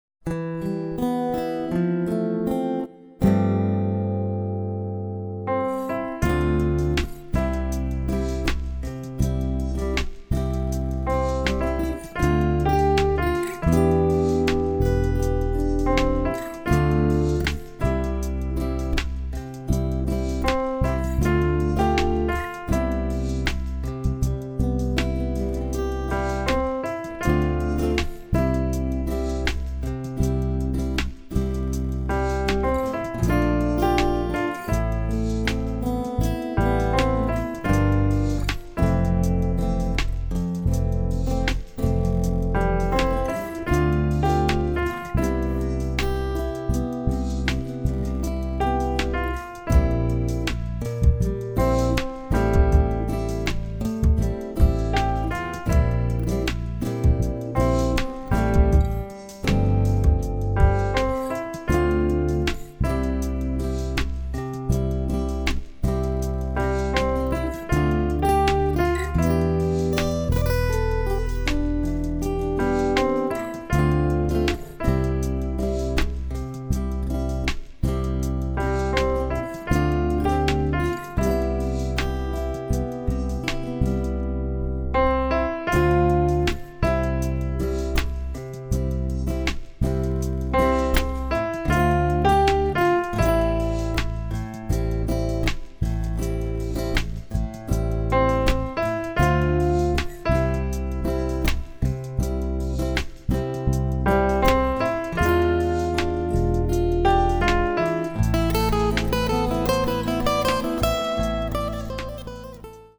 Genre: Ballade